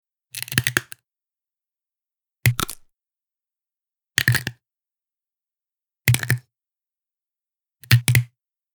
Download Bone Cracking sound effect for free.
Bone Cracking